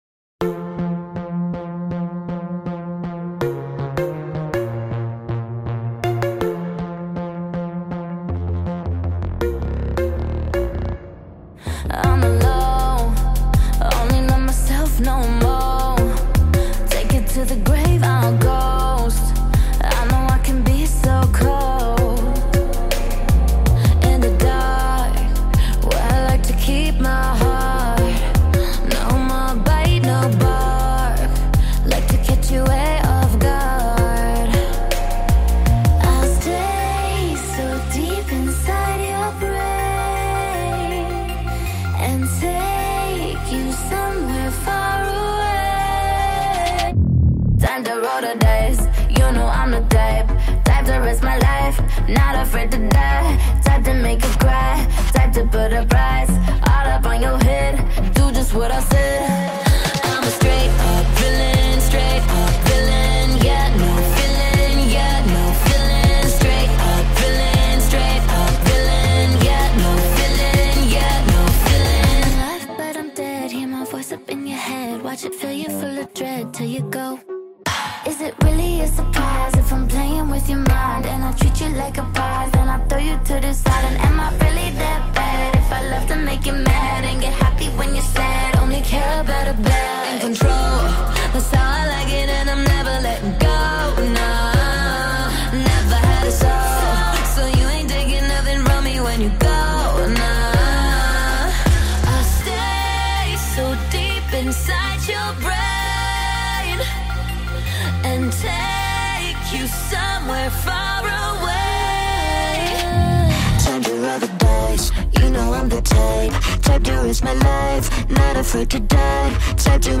Gênero: Pop